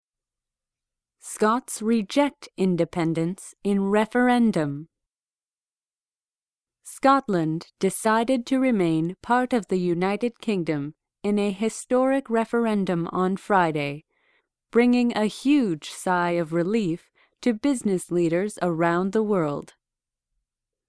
※ここでは標準的なアメリカ英語のリスニングを想定しています。
この英語ナレーションでは「reject」で音を区切ってから「independence」と発音しているので「子音どめ」となります。
この英語ナレーションでは不定冠詞「a」を「エィ」と発音しています。